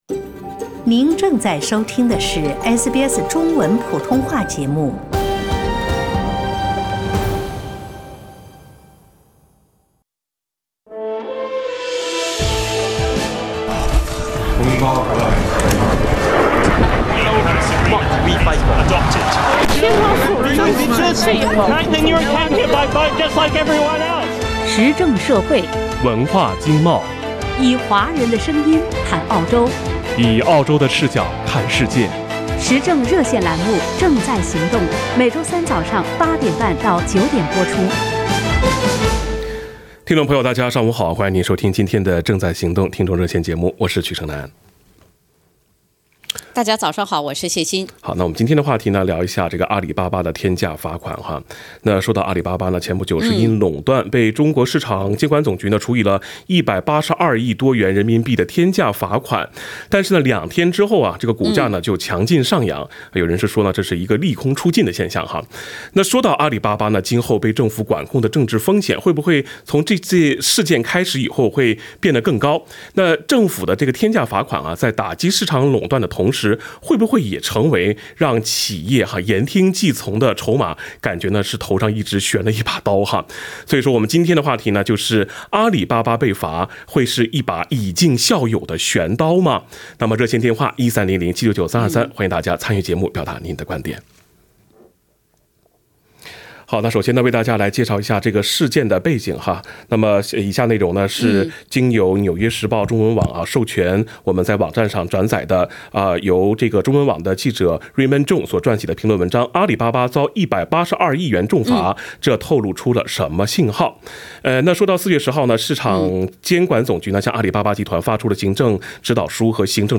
（點擊封面圖片，收聽完整熱線聽眾髮言）